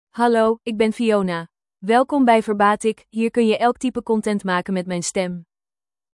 Fiona — Female Dutch AI voice
Fiona is a female AI voice for Dutch (Netherlands).
Voice sample
Listen to Fiona's female Dutch voice.
Female
Fiona delivers clear pronunciation with authentic Netherlands Dutch intonation, making your content sound professionally produced.